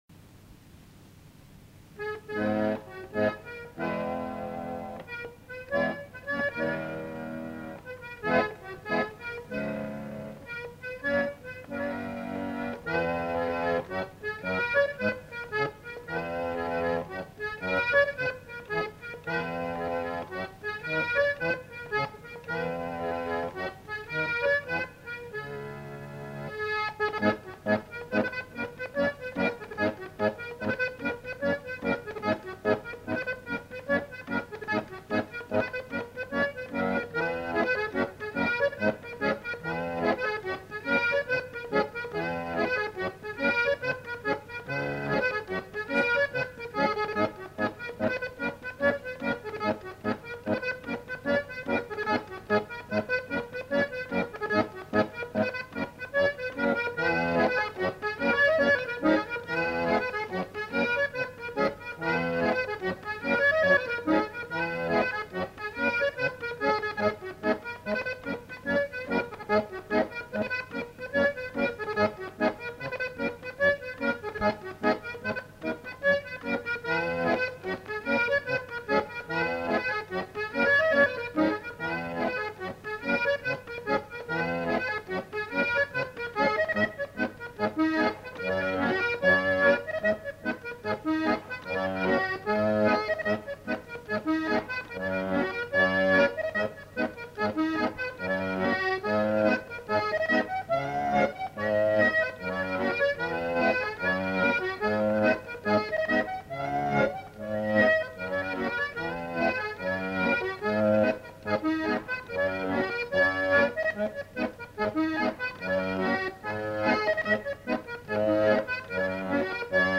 Instrumental. Accordéon diatonique. Bretagne
Aire culturelle : Bretagne
Lieu : Monclar d'Agenais
Genre : morceau instrumental
Instrument de musique : accordéon diatonique
Notes consultables : Gavotte ?